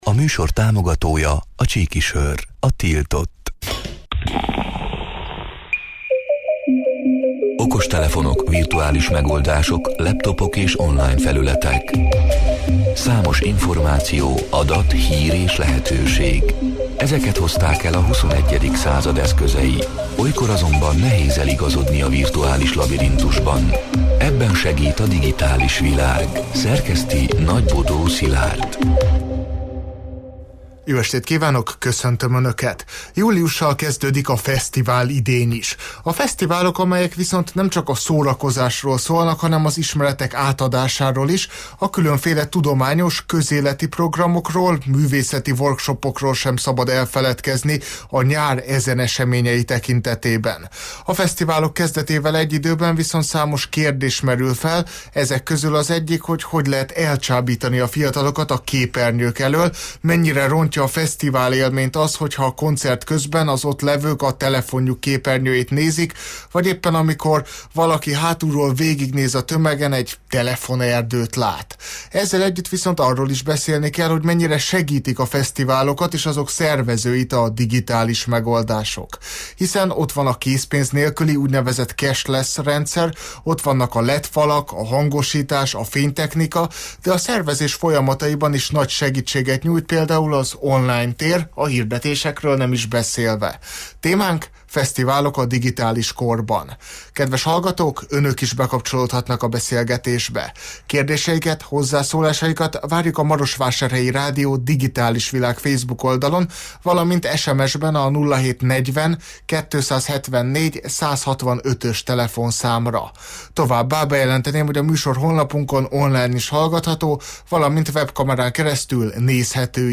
A Marosvásárhelyi Rádió Digitális Világ (elhangzott: 2025. július 1-én, kedden este nyolc órától élőben) c. műsorának hanganyaga: